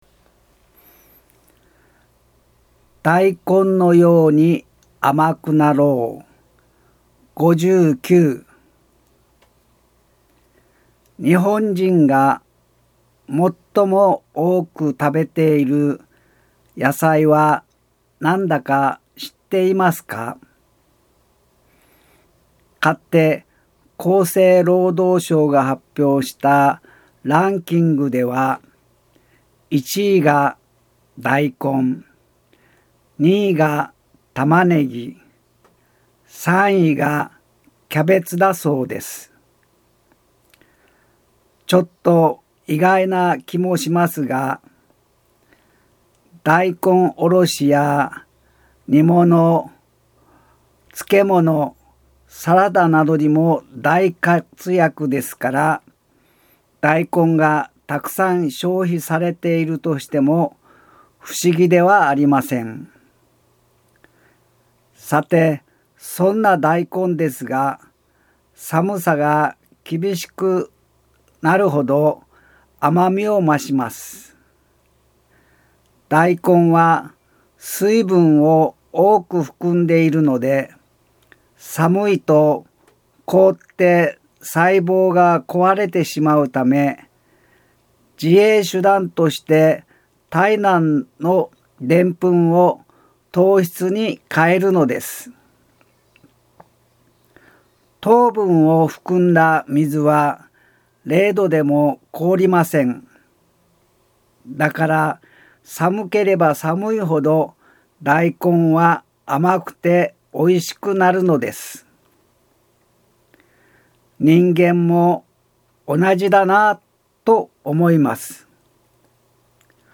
音声ブログ
研修資料のブログの順に、音声でブログを紹介します。